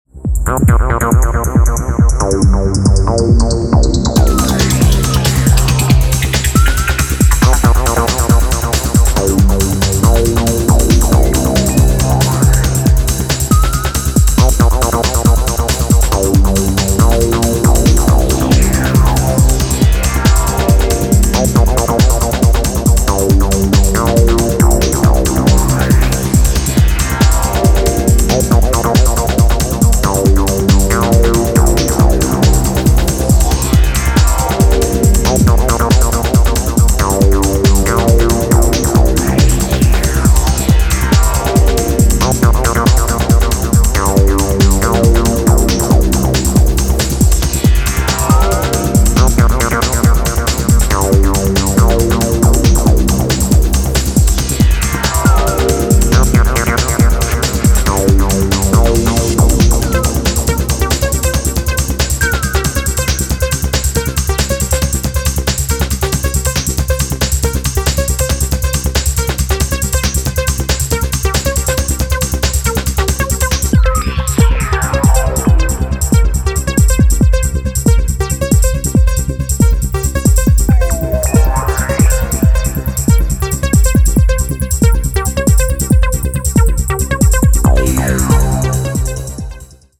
野外レイヴのクライマックスを想定していそうな、かなり気合の入った内容です。